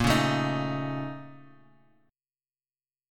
A#mM11 chord